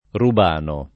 Rubano [ rub # no ]